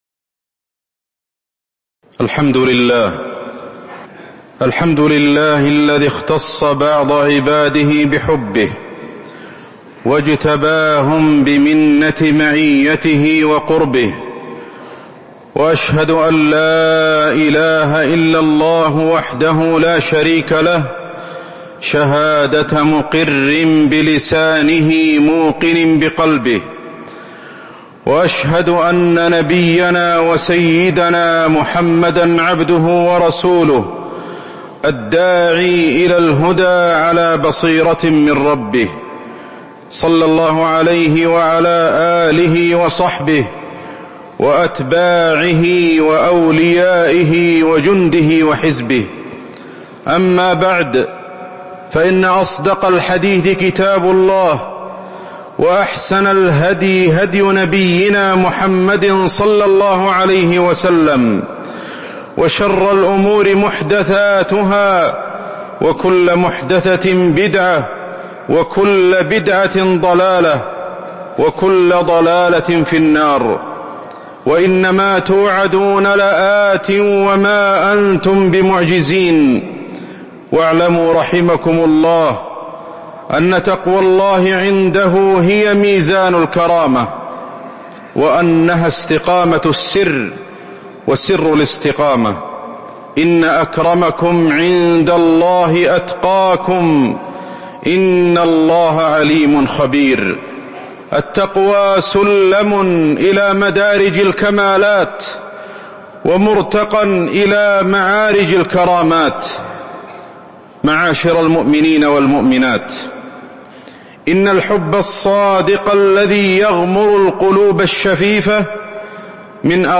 تاريخ النشر ٣ رجب ١٤٤٦ هـ المكان: المسجد النبوي الشيخ: فضيلة الشيخ أحمد بن علي الحذيفي فضيلة الشيخ أحمد بن علي الحذيفي حب الله معناه وآثاره ونتائجه